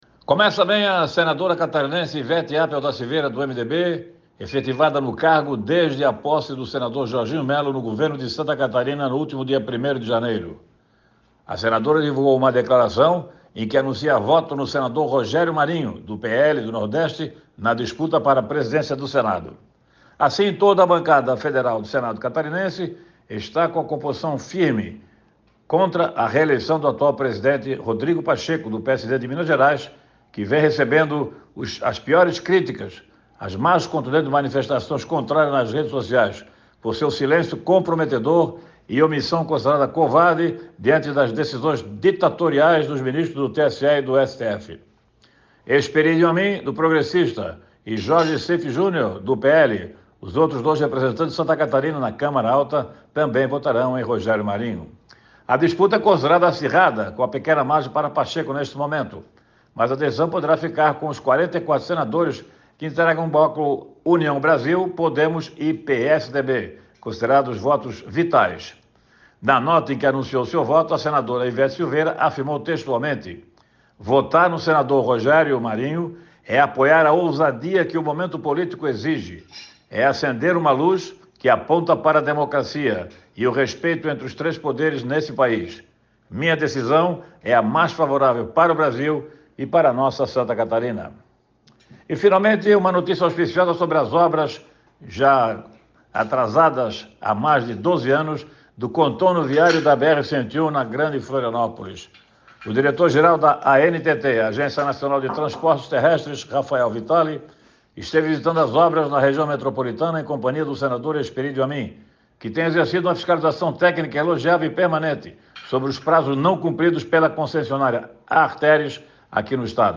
Confira na íntegra o comentário